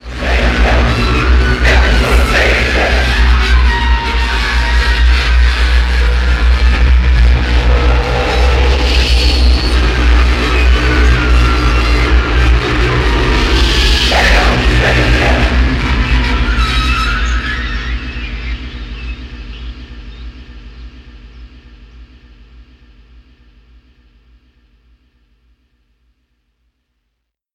Sound Art non-music!
そのあとは強烈な金属打撃系インダスや激ハーシュなど文句なしのサウンドです。